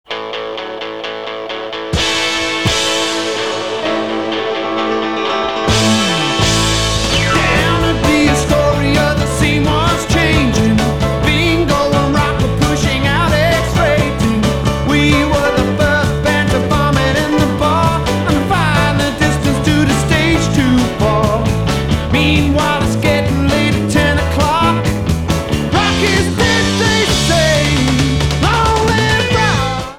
Sound Samples (All Tracks In Stereo Except Where Noted)
Remix version